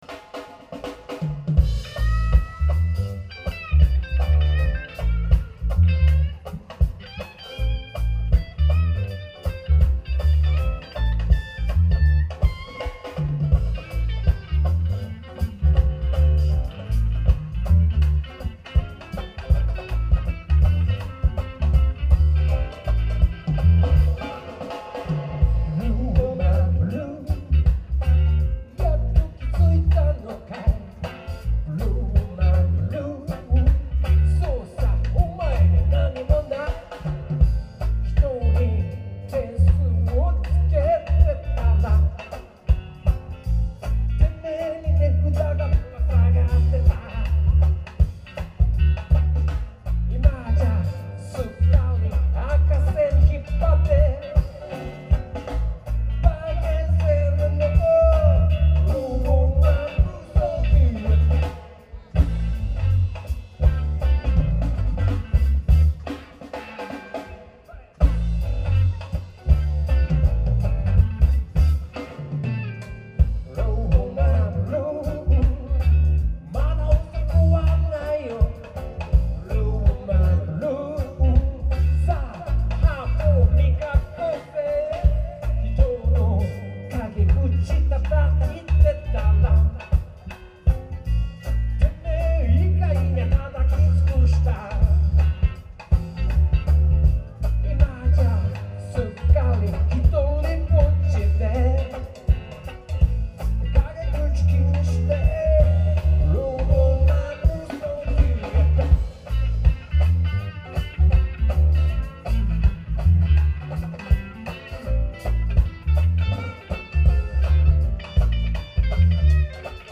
今回のステージはとても広くてナイスでした。
この日のバンドのトップ、2時過ぎからでやっぱり雨、お客さんもまばら。